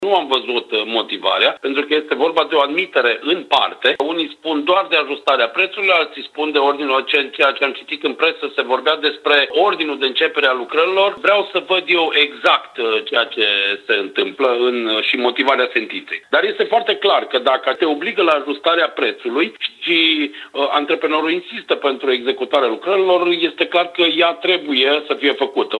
Viceprimarul Cosmin Tabără spune că așteaptă motivarea sentinței pentru că, așa cum arată hotărârea pe scurt, primăria este obligată să actualizeze prețurile, nu și să semneze ordinul de începere a lucrărilor.